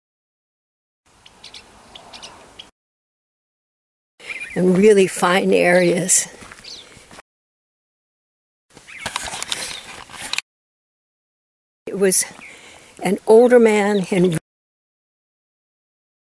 Songbirds say ‘Mountain Lion’ as in the following audio clip. The clearest instance is the first, which is spoken by a small Songbird. After that are three instances of the phrase spoken by a larger Songbird, the California Thrasher.
I hope you will be able to distinguish the phrase, whose pronunciation seems to me to denote the fear and awe inspired in the Songbirds by these ferocious animals …